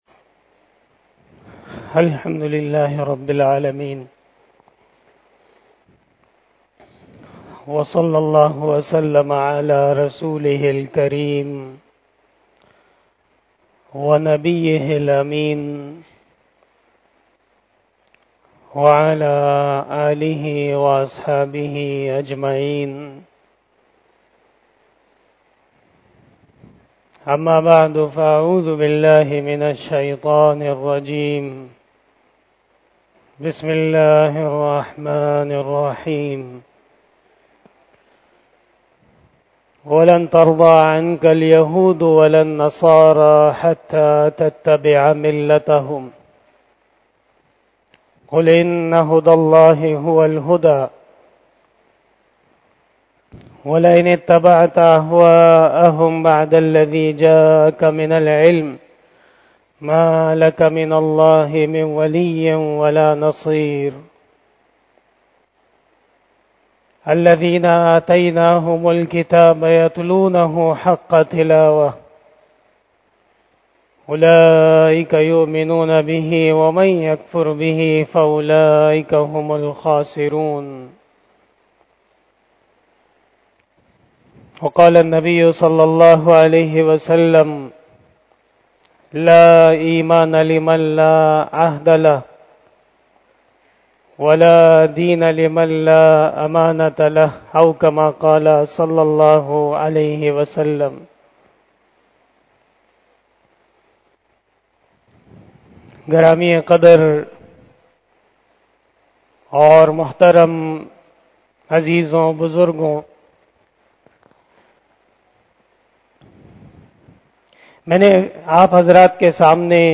10:06 PM 301 Khitab-e-Jummah 2022 --